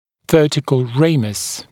[‘vɜːtɪkl ˈreɪməs][‘вё:тикл ˈрэймэс]вертикальная часть ветви нижней челюсти